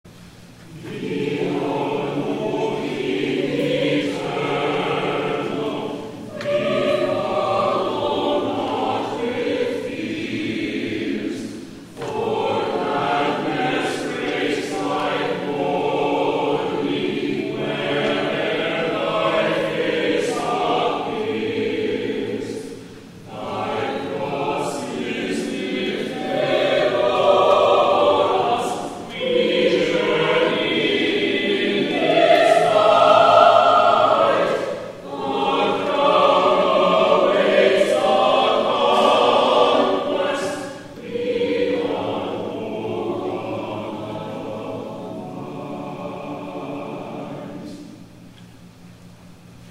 *THE CHORAL RESPONSE